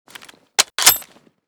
k98_open.ogg